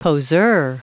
Prononciation du mot poseur en anglais (fichier audio)
Prononciation du mot : poseur